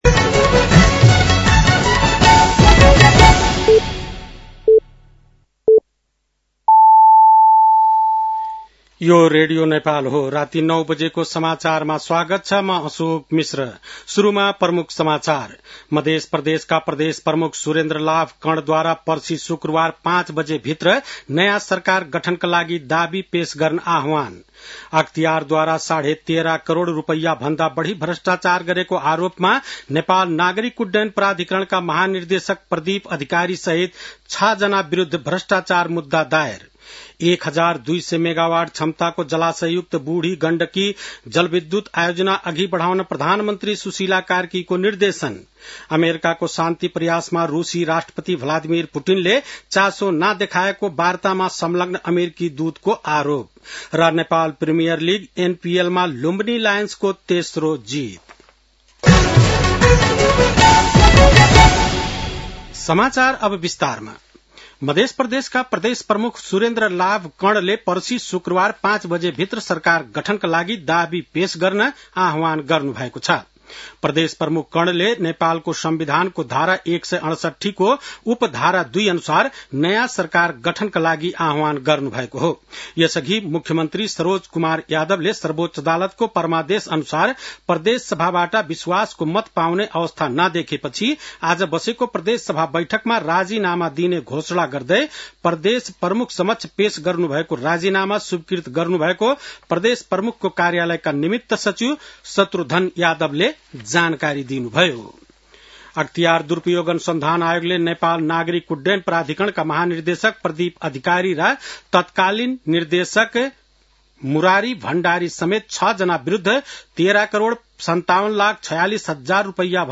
बेलुकी ९ बजेको नेपाली समाचार : १७ मंसिर , २०८२